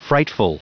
Prononciation du mot frightful en anglais (fichier audio)
Prononciation du mot : frightful